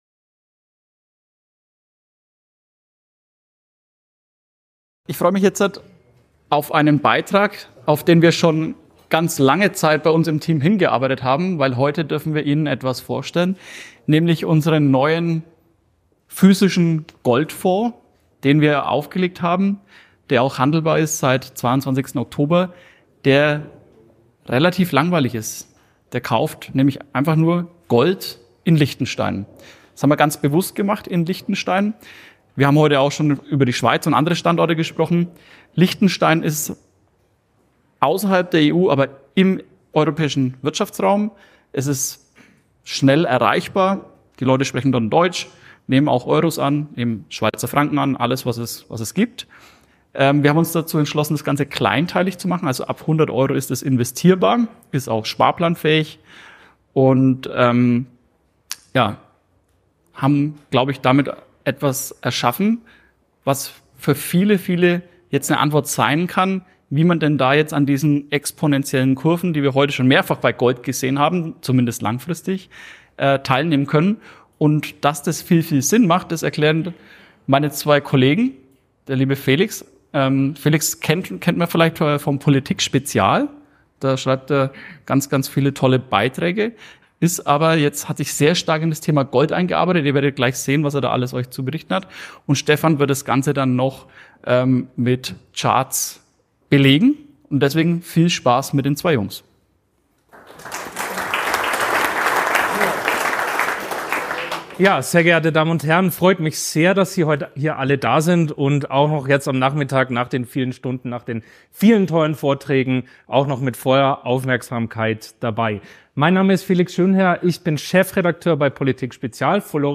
In diesem aufschlussreichen Vortrag